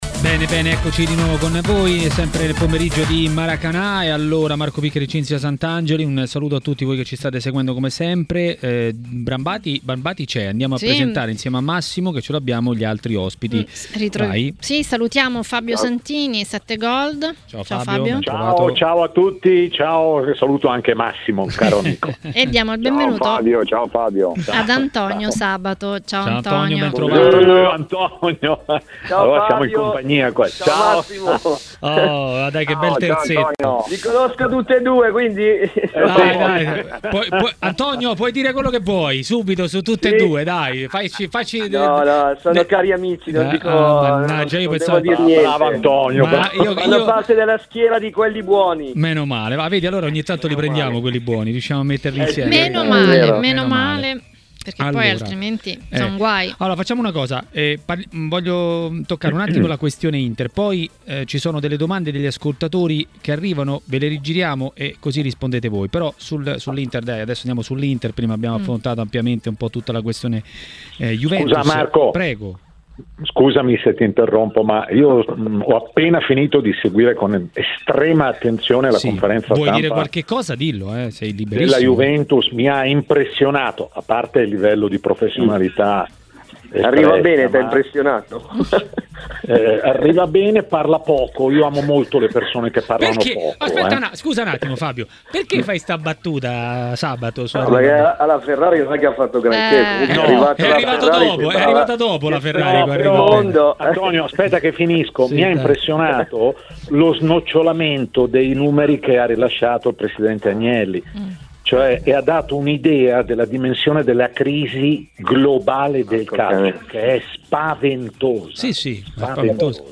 L'ex calciatore Antonio Sabato a TMW Radio, durante Maracanà, ha parlato delle vicende in casa Inter.